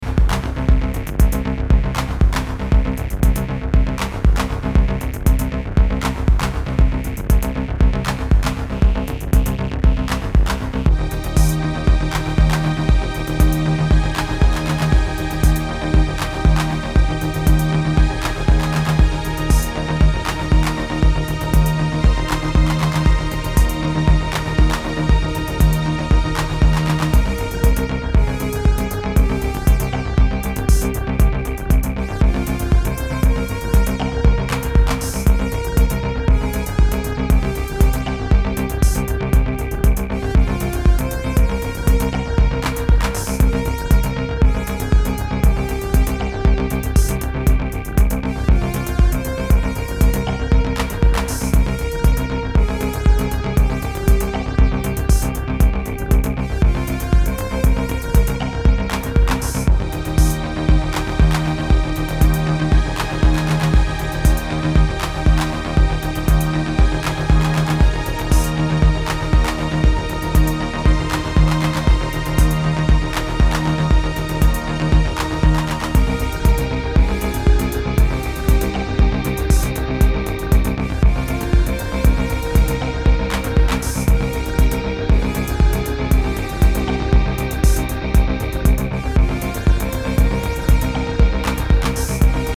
solo analog synth project
Electro